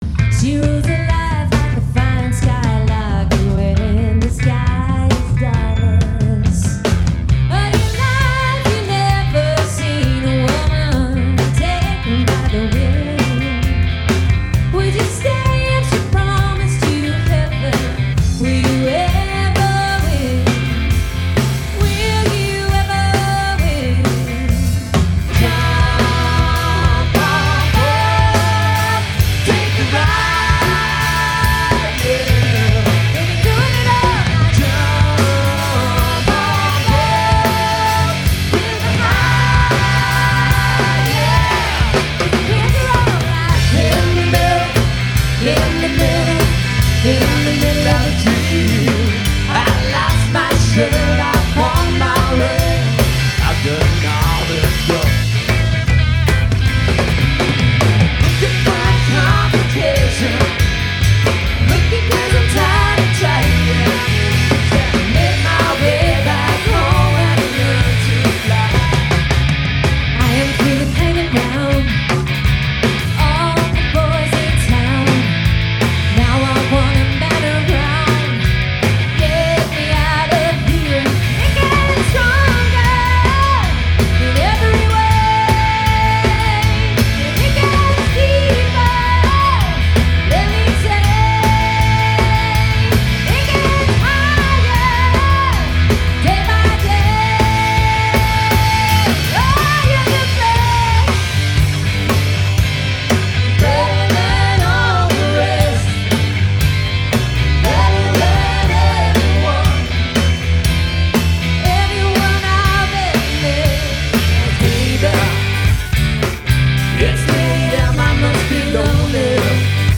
Melbourne’s quintessential rock covers band